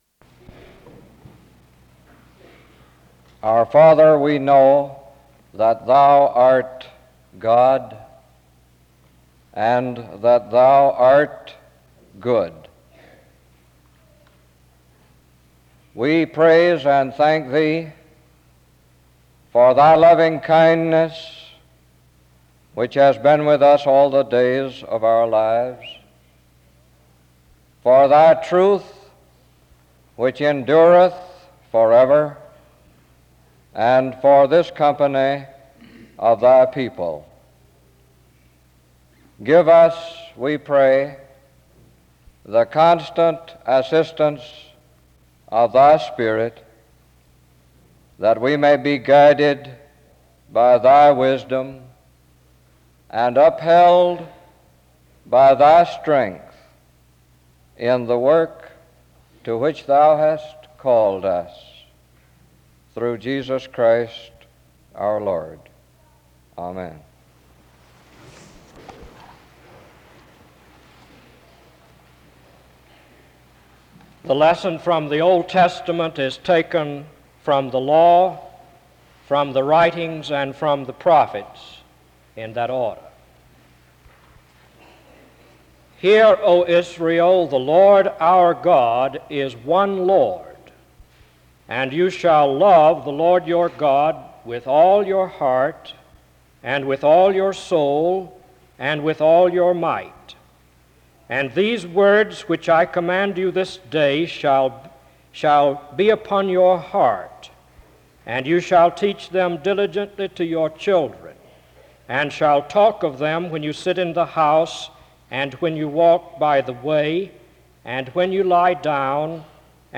The service begins with prayer (00:00-01:12), and Deuteronomy 6:4-9, 6:20-25, Psalm 121, Isaiah 55 is read (01:13-07:13). Next, Scripture is read aloud from Ephesians 3:14-21 (07:14-08:40).
He exhorts the graduates to blend their knowledge with this right spirit which honors God (15:53-25:12). A closing prayer is offered (25:13-25:40).